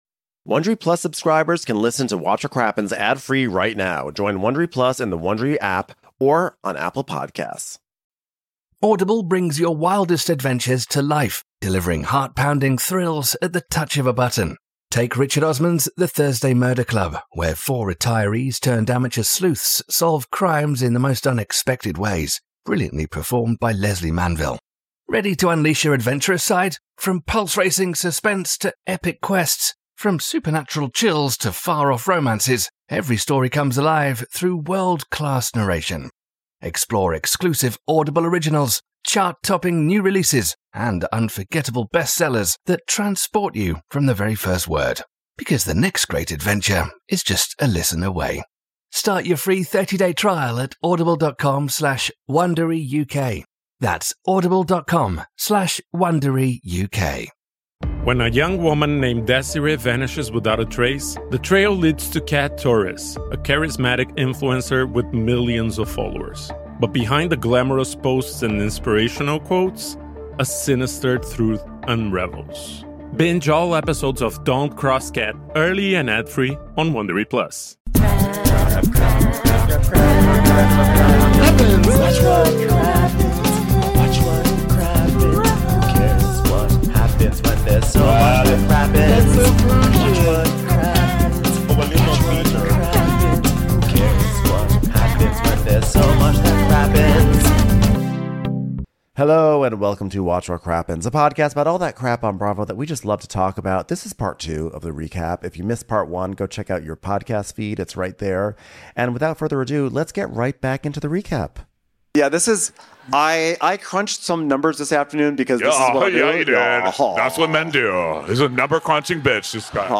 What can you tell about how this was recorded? We are live in Austin to recap summer house! Carl has secured a 90 year lease for his possibly pointless business and things are looking … soft?